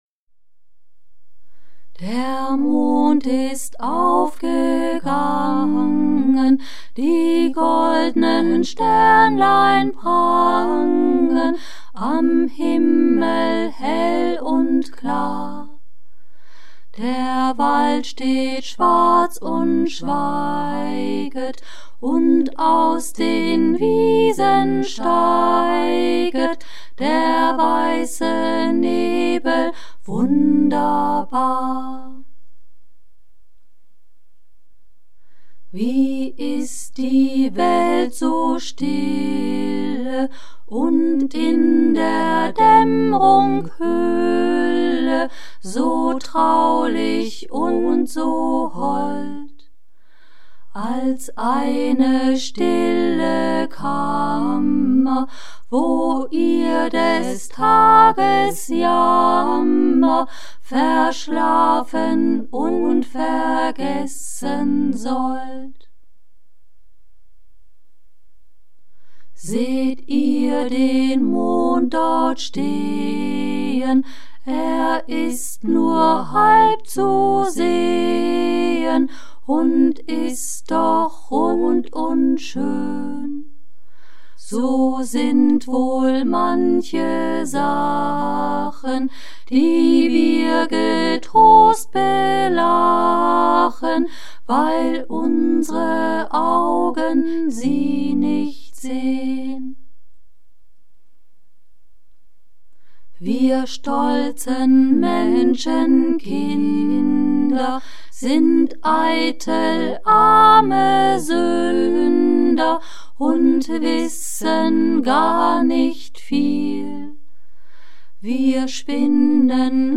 Musik aus dem wahren Herzen gesungen
Ukulele